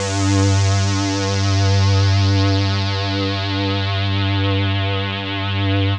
Index of /90_sSampleCDs/Trance_Explosion_Vol1/Instrument Multi-samples/Angry Trance Pad
G3_angry_trance_pad.wav